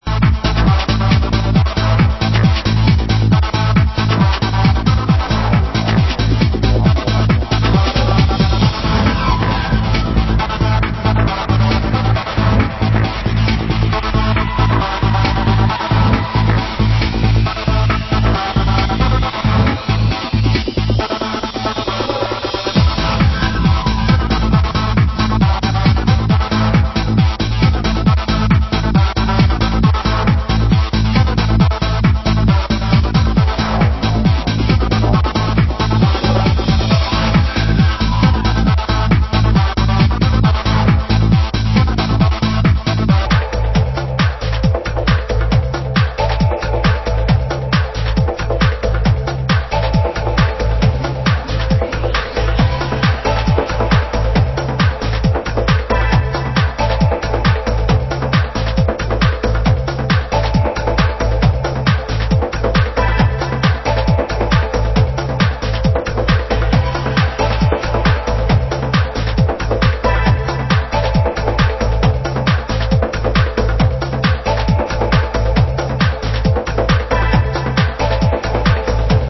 Genre: Progressive